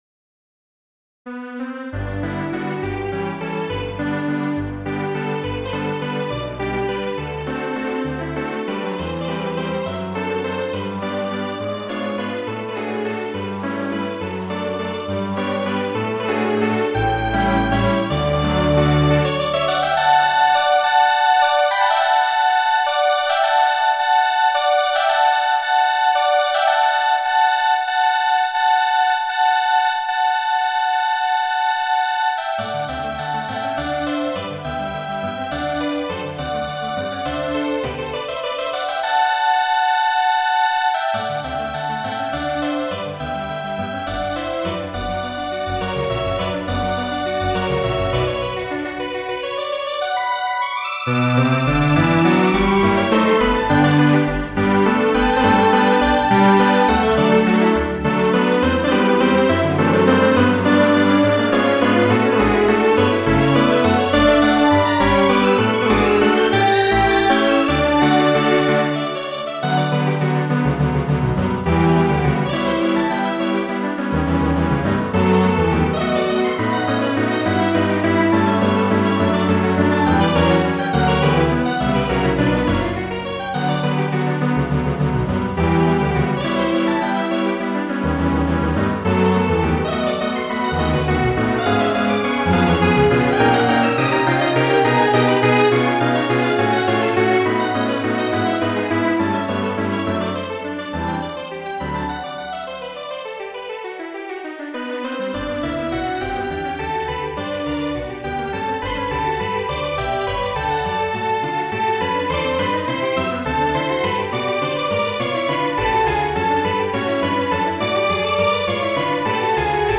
Valso, tria parto de la Noktamuziko por kordoj, de Petro Ludoviko Ĉejkovski.